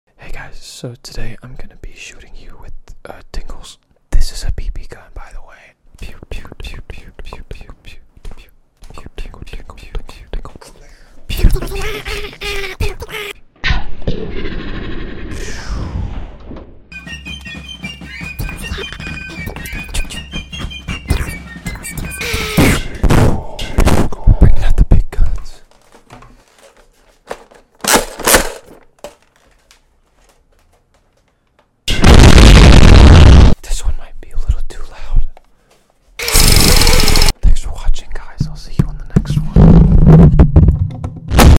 ASMR Shooting YOU With Tingles! sound effects free download